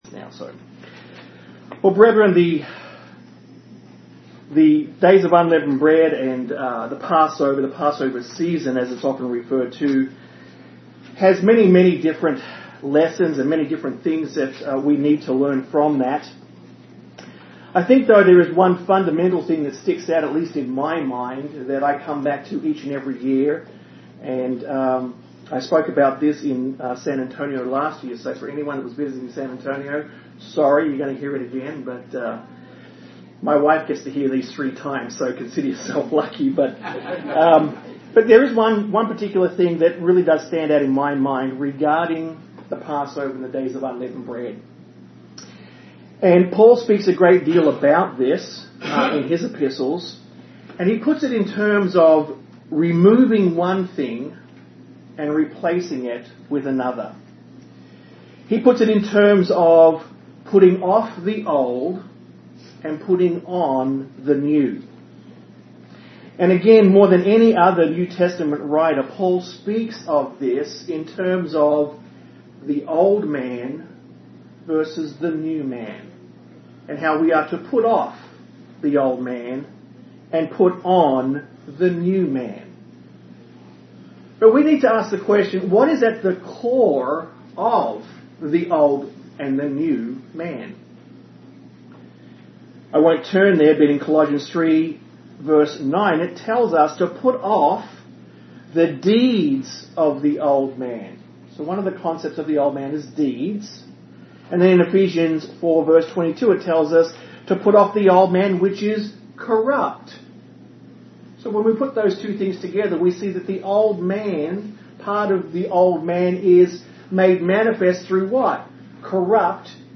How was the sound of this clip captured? Given in Austin, TX